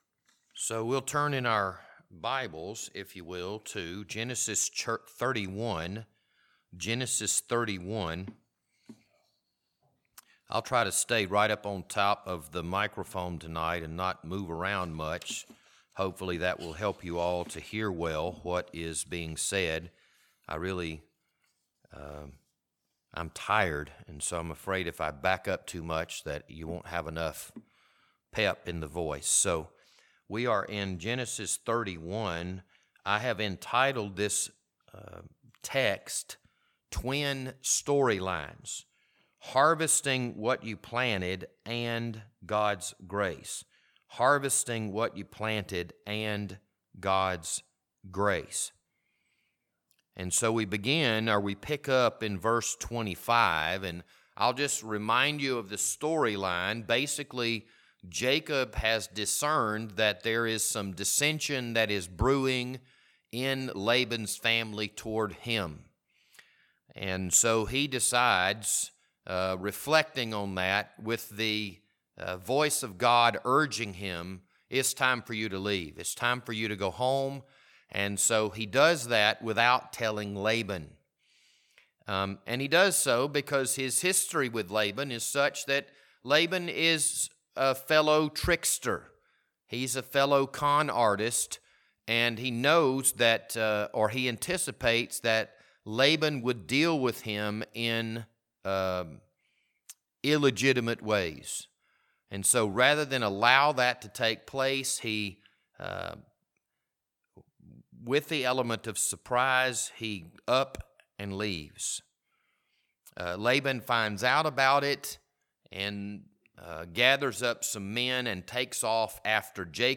This Wednesday evening Bible study was recorded on September 20th, 2023.